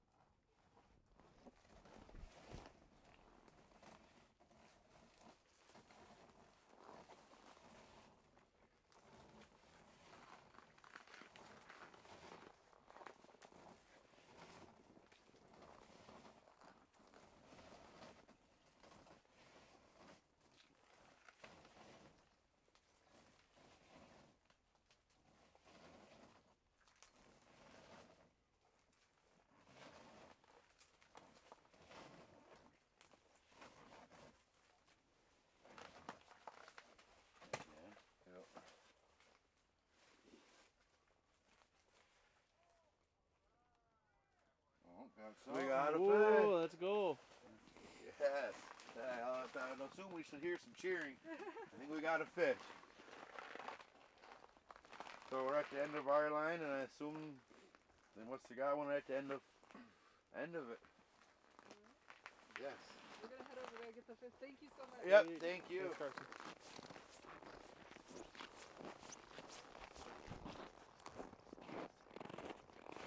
Index of /SkyCloud/Audio_Post_Production/Education/The Ways/2026-01-29 Ice Fishing Camp - Mole Lake/Day 3 - 1-31